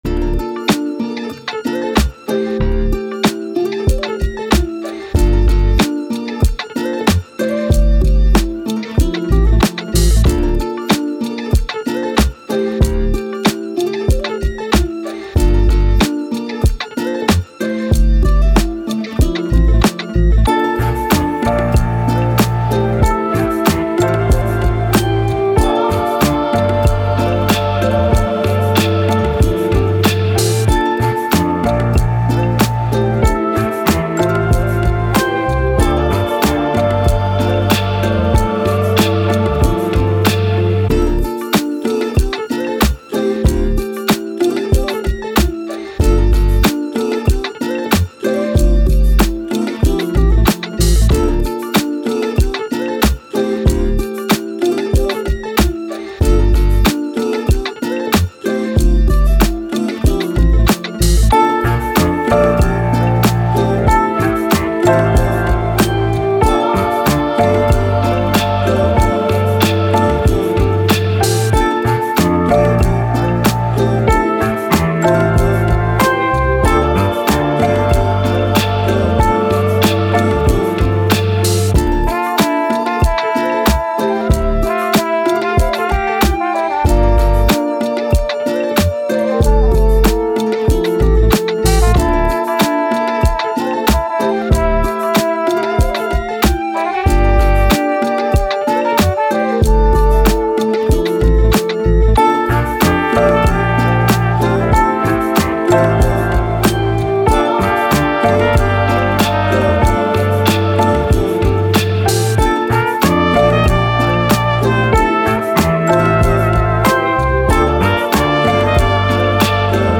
Hip Hop, Boom Bap, Upbeat, Vibe, Positive, Playful